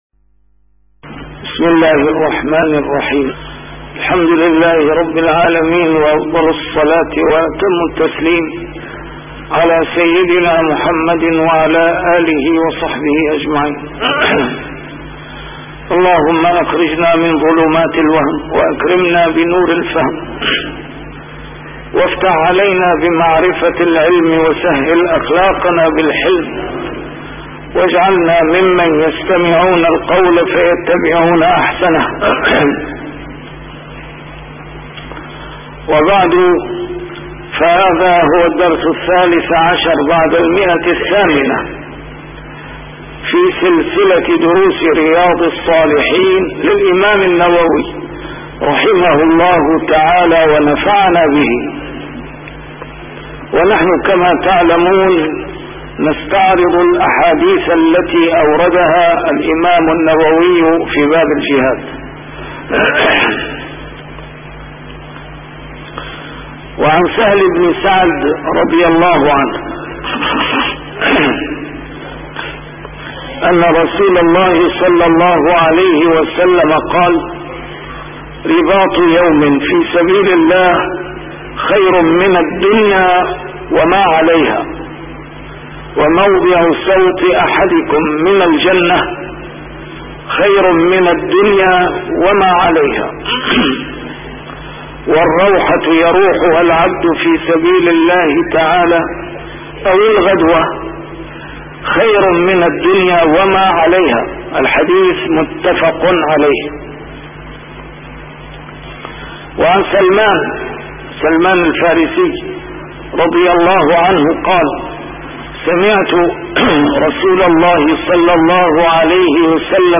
A MARTYR SCHOLAR: IMAM MUHAMMAD SAEED RAMADAN AL-BOUTI - الدروس العلمية - شرح كتاب رياض الصالحين - 813- شرح رياض الصالحين: فضل الجهاد